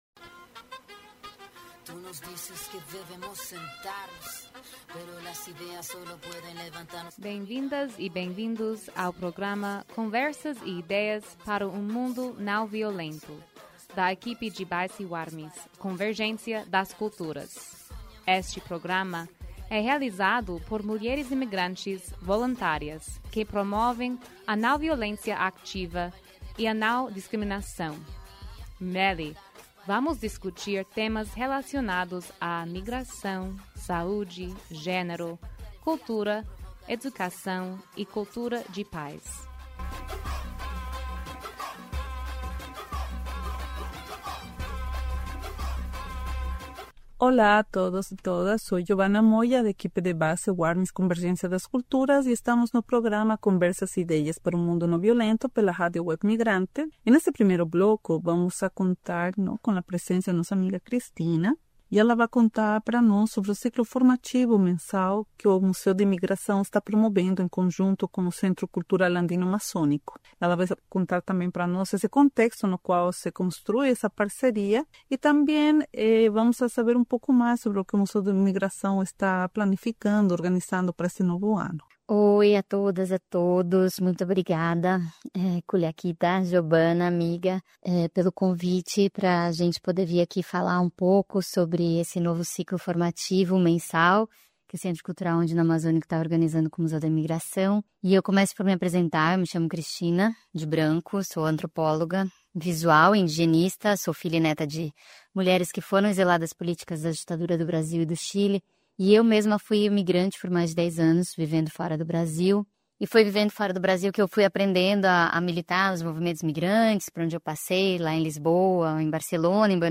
tem convidadas especiais no programa, para falar acerca do Ciclo formativo mensal que o Museu da Imigração está promovendo em parceria com o Centro Cultural Andino Amazônico(CCAA).